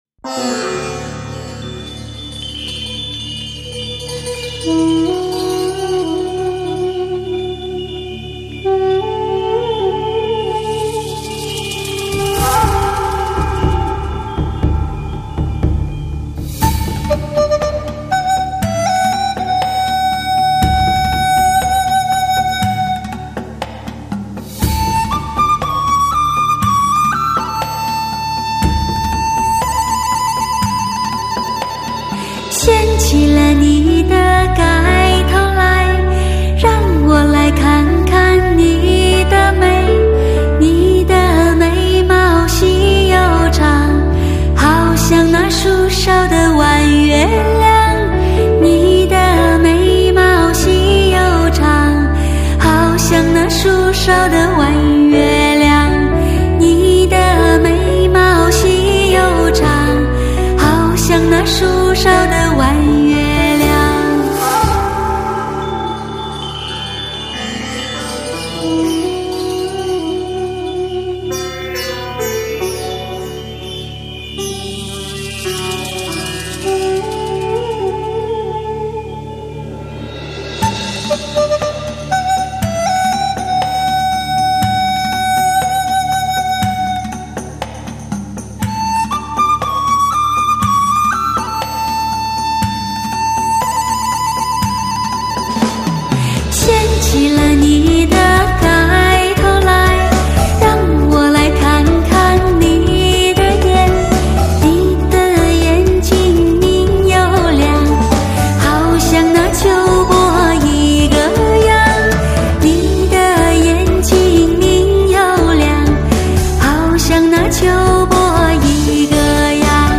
Surround 7.1德国技术STS三维高临场音效、美国最新技术高清录音 紫水晶CD
专有STS Magix 母带制作，STS magix virtual live高临场感CD。